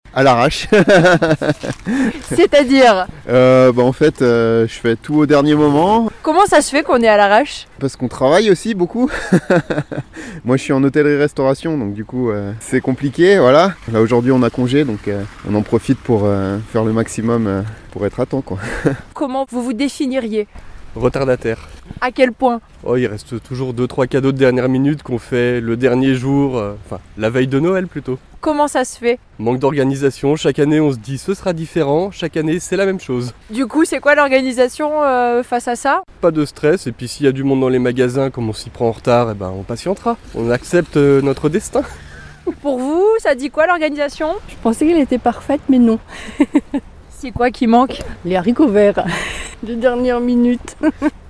ITC Micrott retardataires 1- Noel 2024 (51’s)